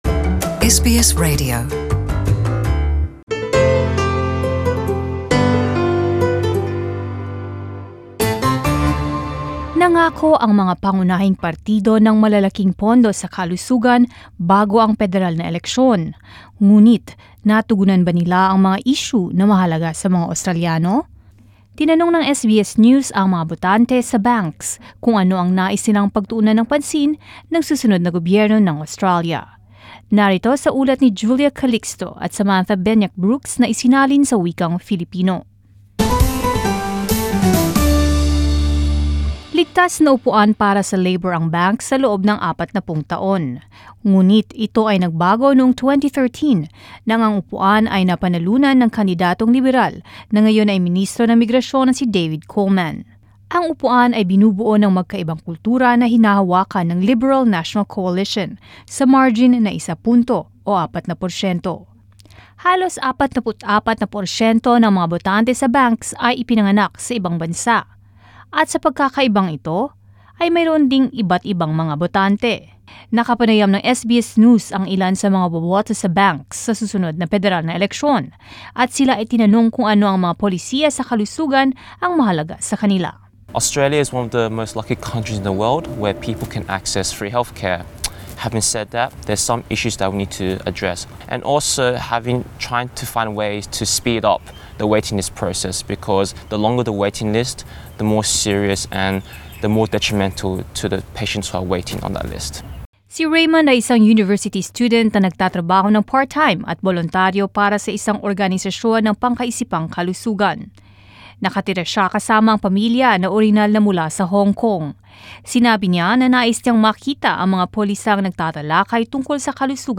Nangako ang mga pangunahing partido ng malalaking pondo sa kalusugan bago ang pederal na eleksyon, ngunit natugunan ba nila ang mga isyu na mahalaga sa mga Australyano? Tinanong ng SBS News ang mga botante sa Banks kung ano ang nais nilang pagtuunan ng pansin ng susunod na gobyerno ng Australya.